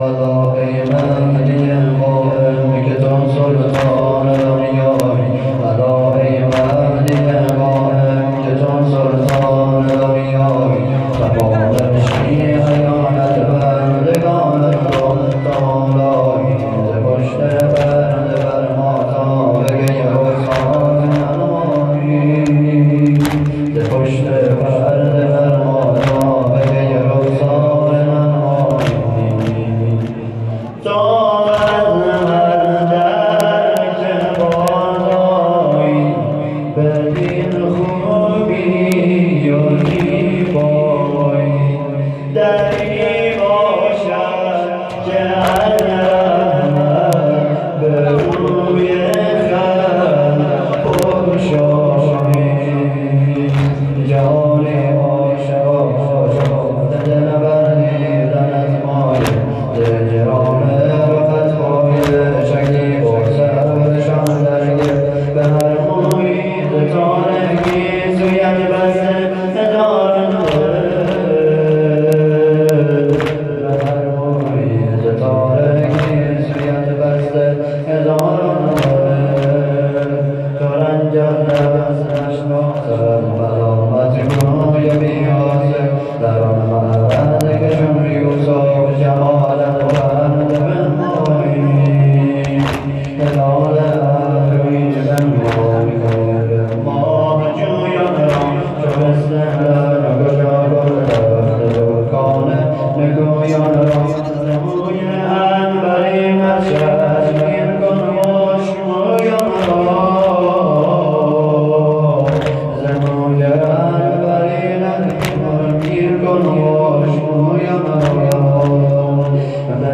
مداحی زابلی
6-شلاقی-الا-ای-مهدی-زهرا....mp3